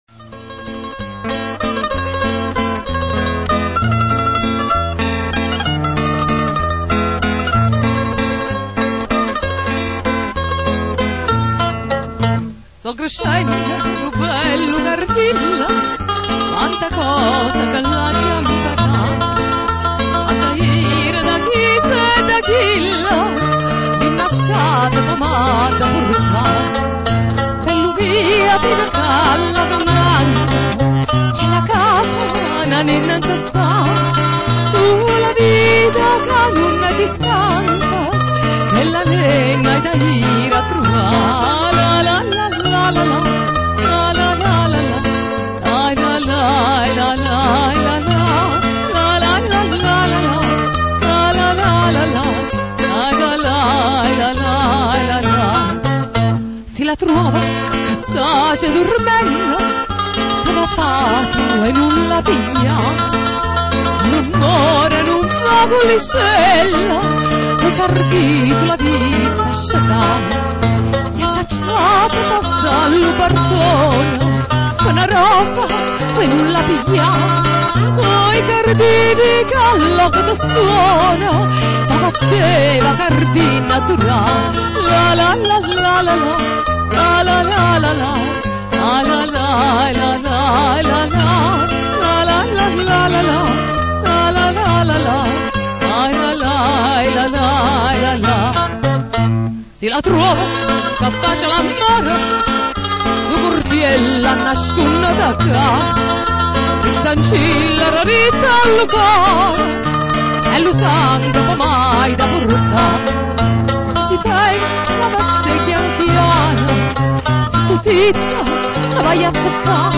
Ascolta Classiche vesuviane cantate da Napoli Antica Se non si ascolta subito il suono attendere qualche secondo, solo la prima volta